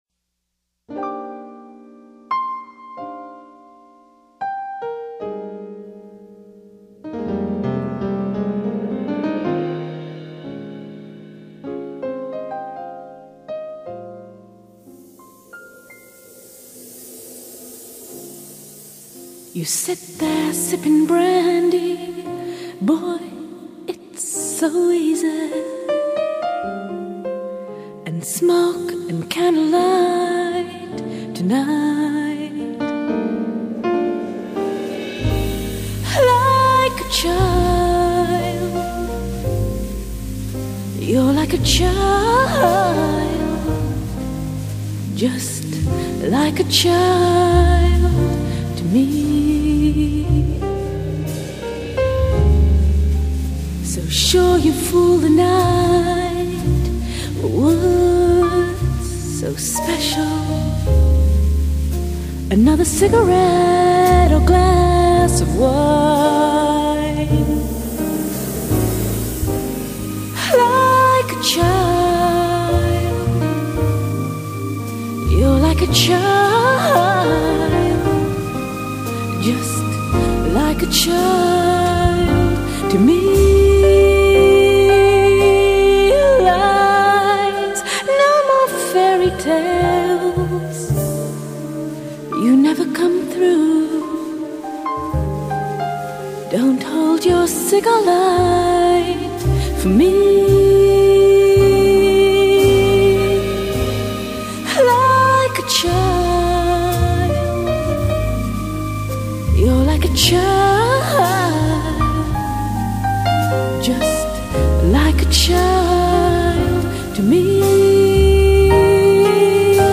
音樂類型 : 爵士
☆優雅的現代爵士風